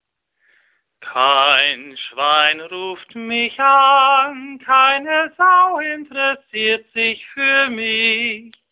gesungen vom lyrischen Tenor